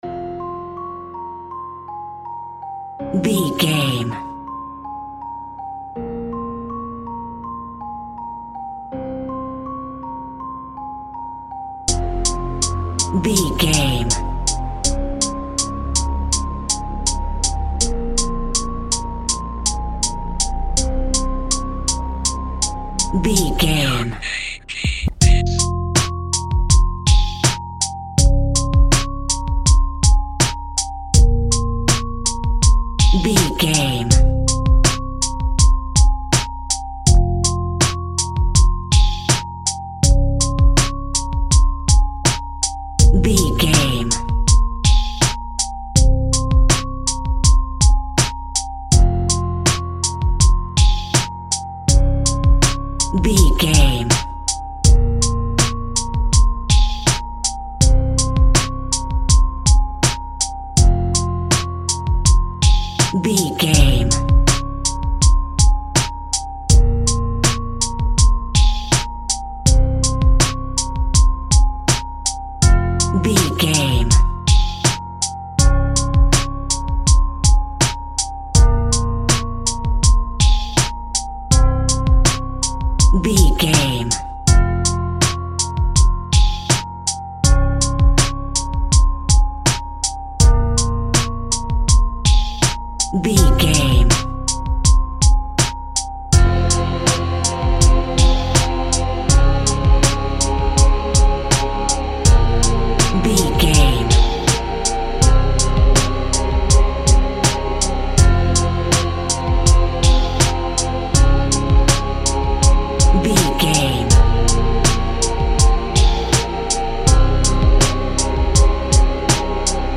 Hip Hop Music for a Scary Film.
Aeolian/Minor
tension
ominous
dark
haunting
eerie
drums
piano
electric organ
synthesiser
instrumentals